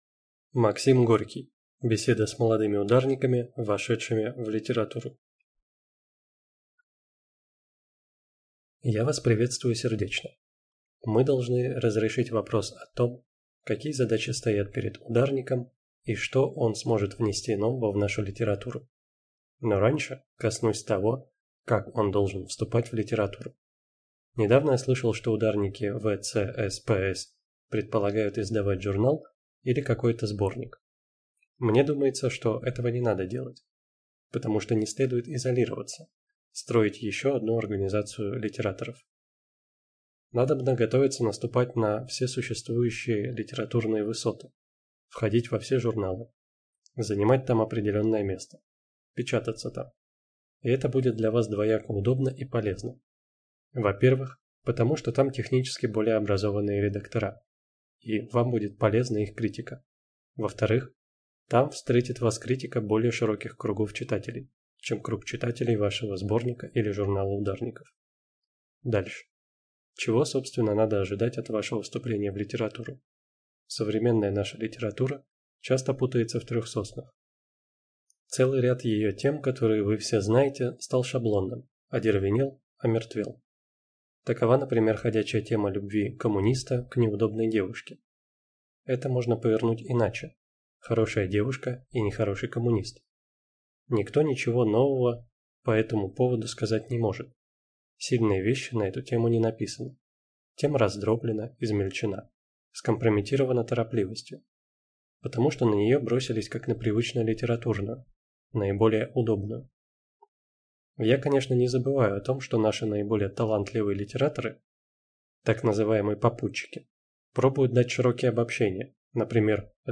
Аудиокнига Беседа с молодыми ударниками, вошедшими в литературу | Библиотека аудиокниг